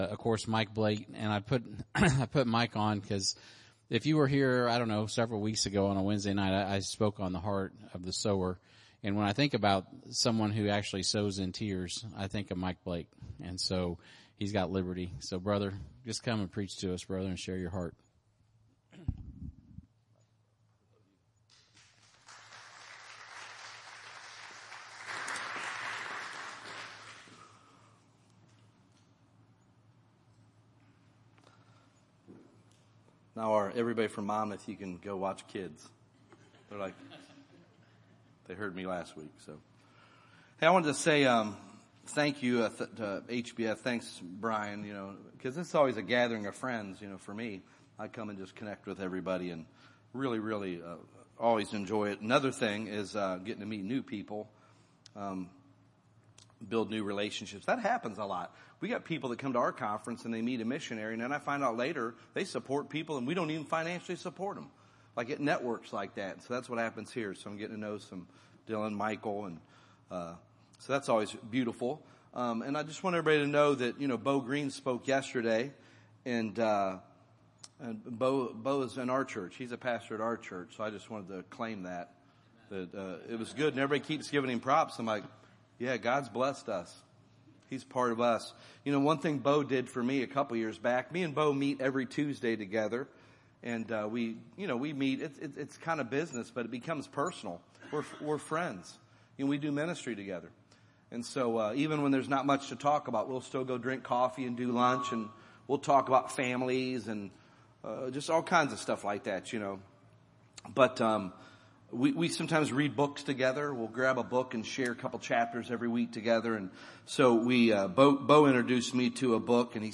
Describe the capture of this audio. Tuesday Session 2 2026 Vision Conference